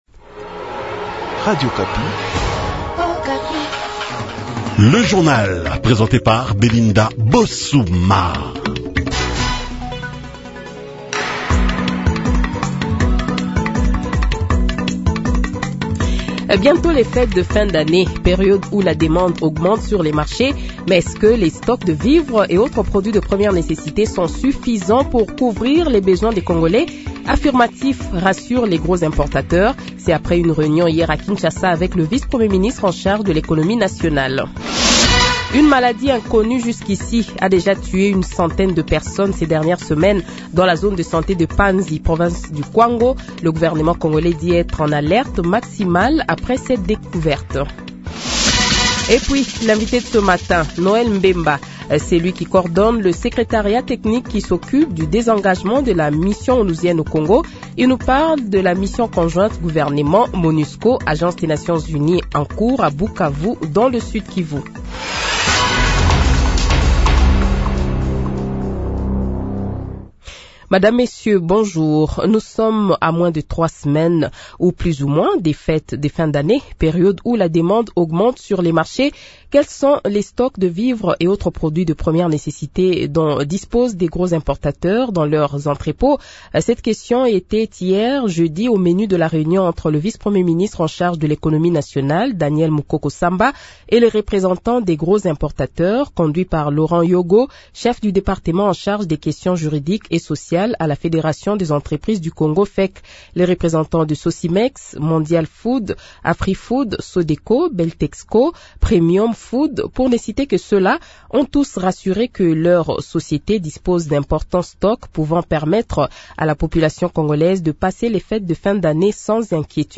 Le Journal de 7h, 06 Decembre 2024 :